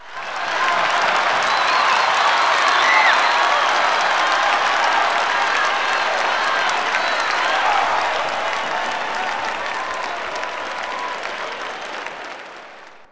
clap_050.wav